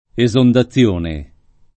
esondazione [ e @ onda ZZL1 ne ] s. f.